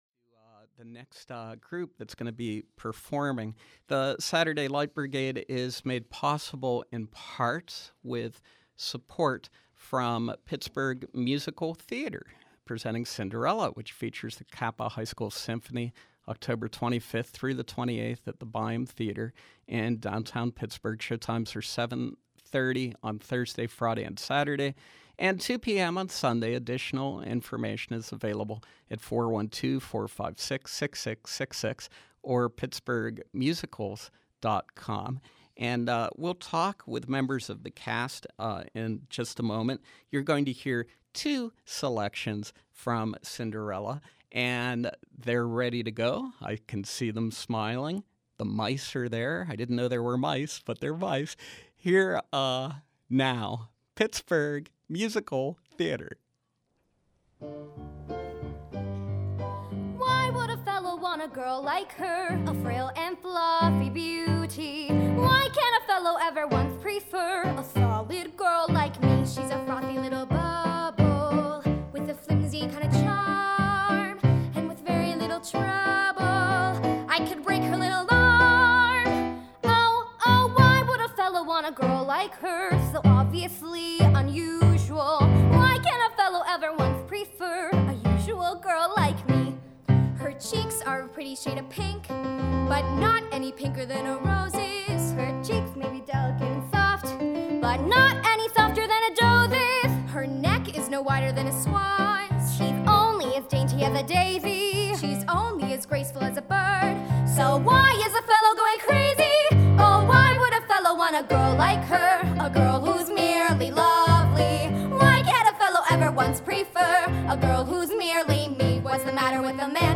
From 10/20/12: Cast members from Pittsburgh Musical Theater’s upcoming production of Cinderella , 10/25 to 10/28, Byham Theater